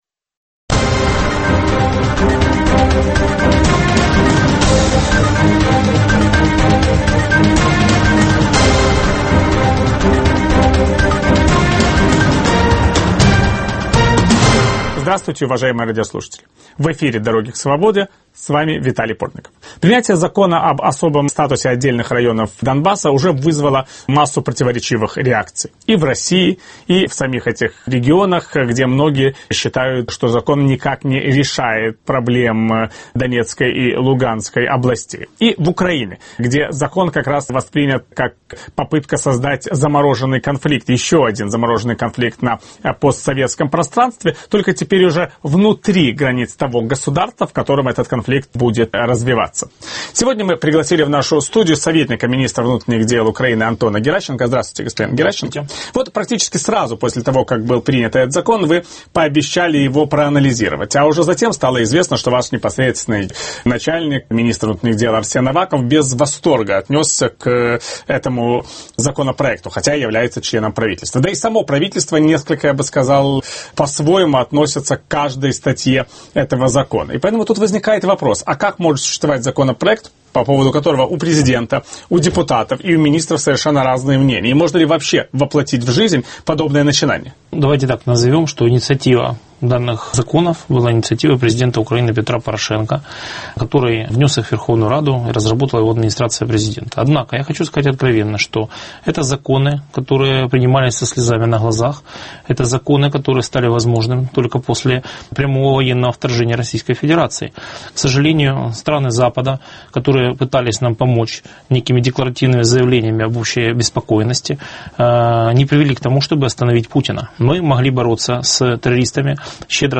В программе"Дороги к свободе" Виталий Портников беседует с советником министра внутренних дел Украины Антоном Геращенко.